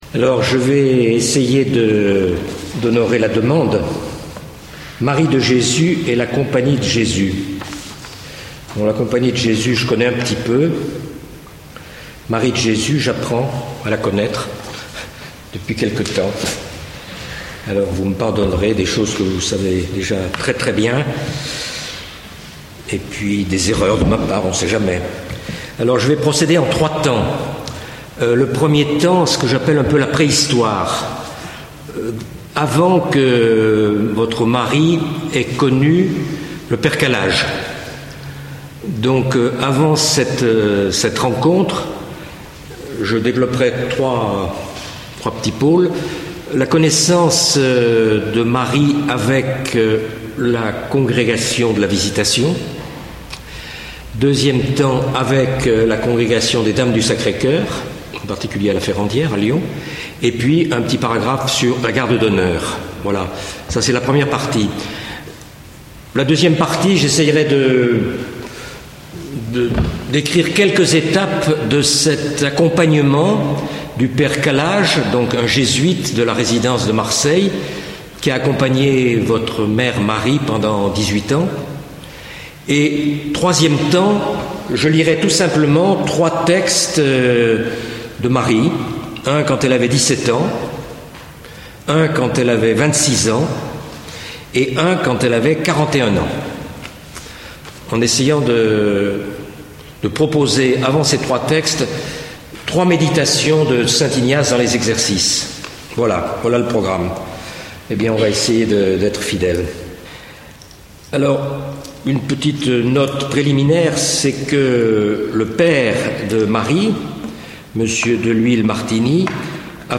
Enseignement
Cycle de conférences sur la Bse Mère Marie de Jésus données au monastère de la Servianne des Filles du Sacré Coeur de Jésus à l'occasion du 125ème anniversaire de la naissance au Ciel de leur fondatrice, la Bienheureuse Mère Marie de Jésus.